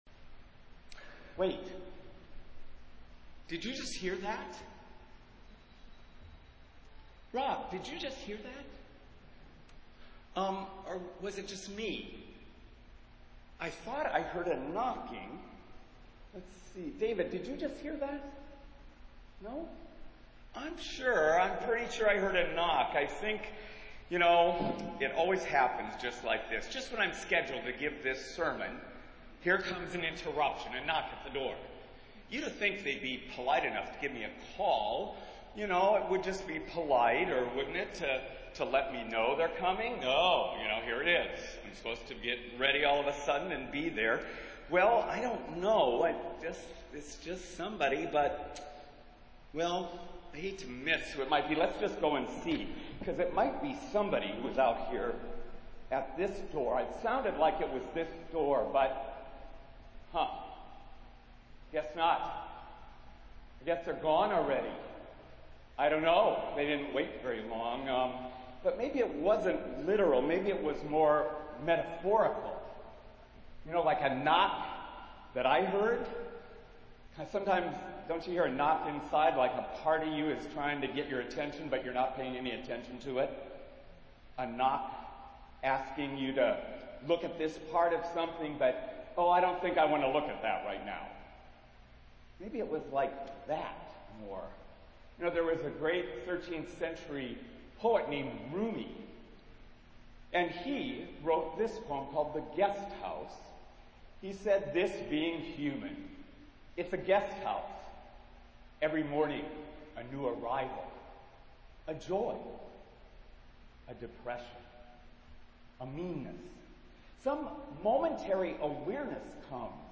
Festival Worship - Twelfth Sunday after Pentecost